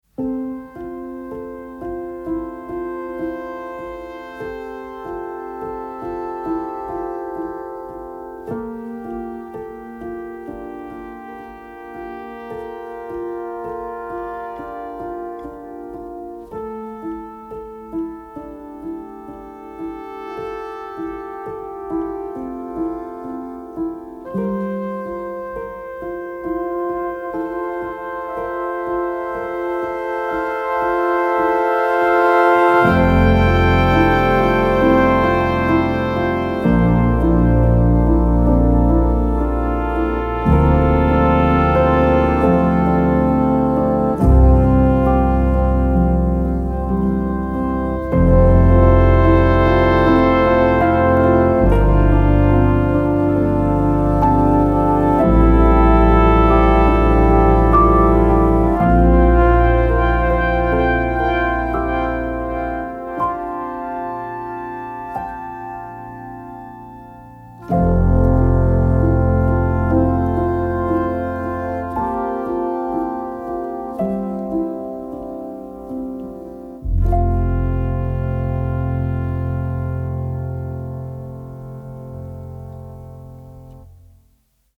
饱含情感的铜管乐声
从单一音符或和弦出发，创造出交织变化的铜管乐句
这不仅能让你得到现实生活中的大乐队合奏声，也可以获得每件乐器的细微纹理，它还允许你对每一件乐器进行单独混音和声音处理。
乐器类型: 铜管合奏